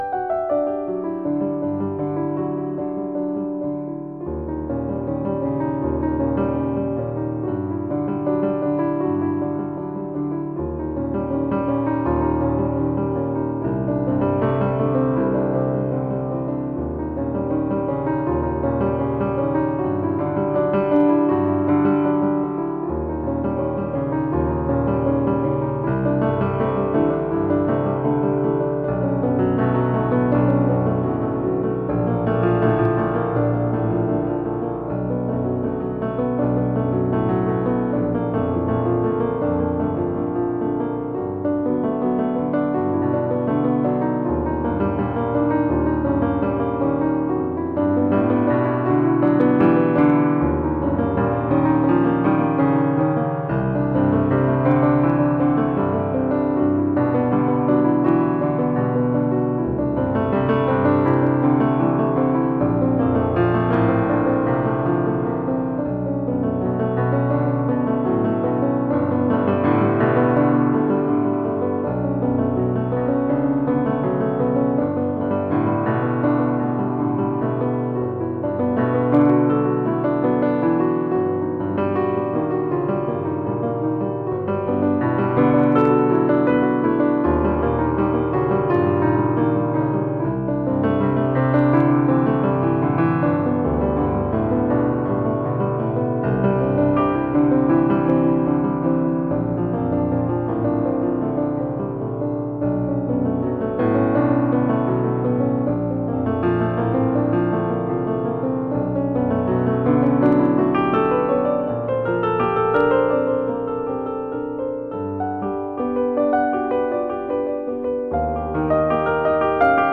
Stage of Contact improvisation, February 7th: improvisation.